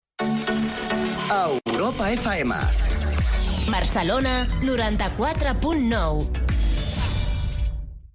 Indicatiu de l'emissora i freqüència a Barcelona.